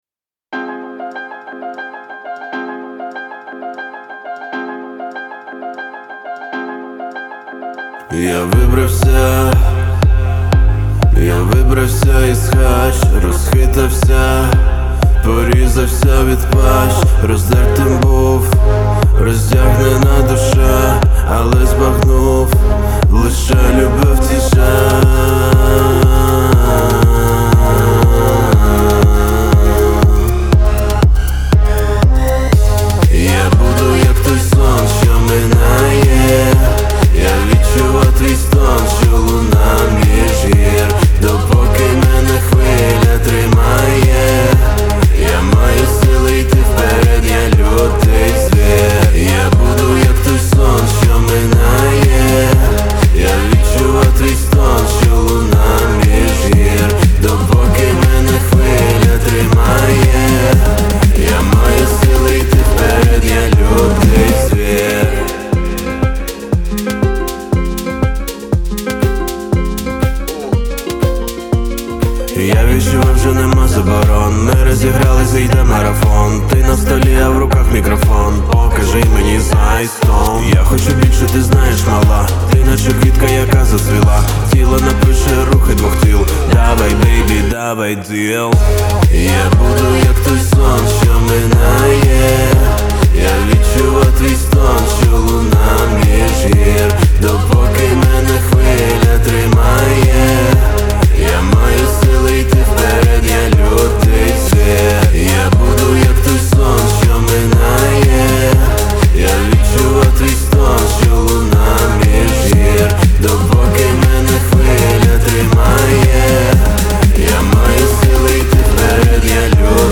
• Жанр: Pop, Rap